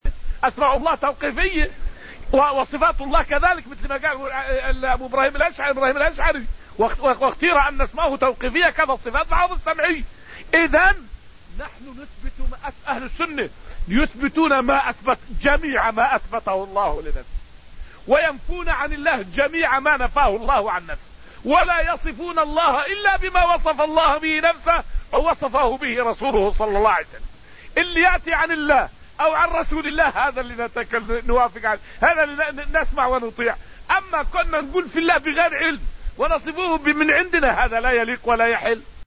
فائدة من الدرس الثالث من دروس تفسير سورة الحديد والتي ألقيت في المسجد النبوي الشريف حول أسماء الله تعالى توقيفية.